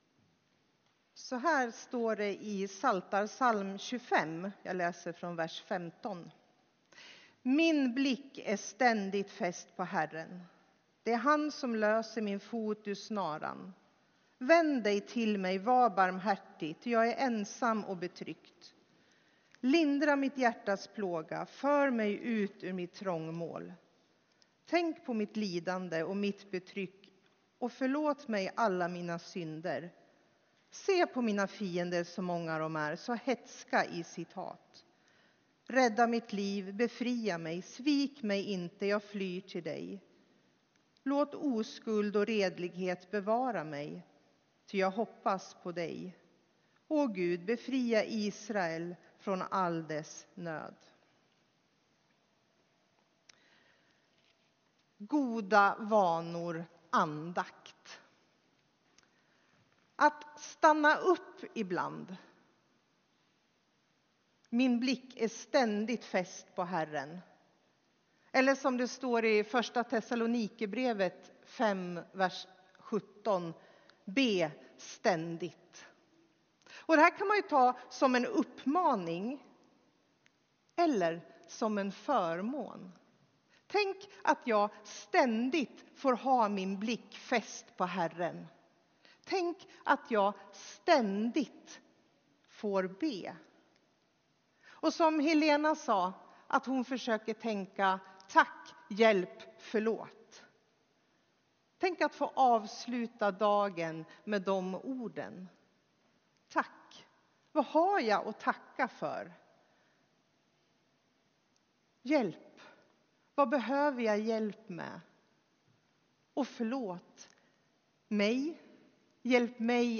Tema för gudstjänsten är ”Goda vanor: andakt”. Texten är hämtad från Psaltaren 25:12-22.